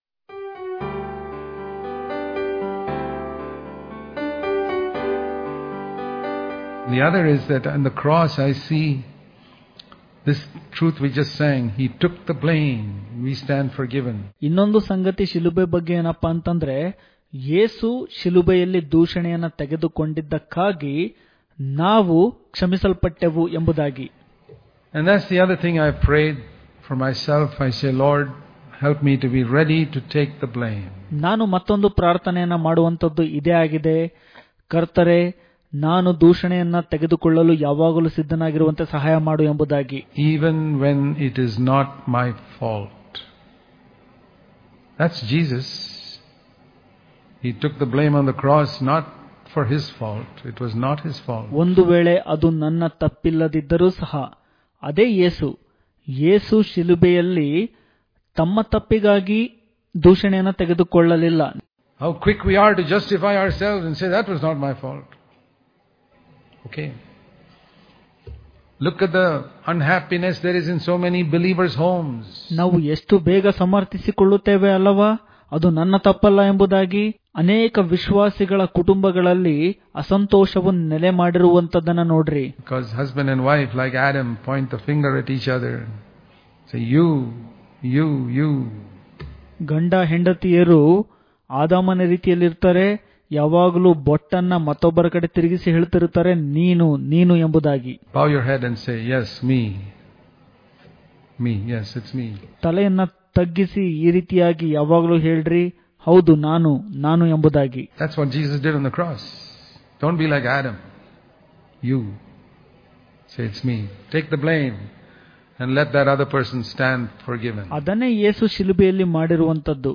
July 26 | Kannada Daily Devotion | Take The Blame And Forgive Others Daily Devotions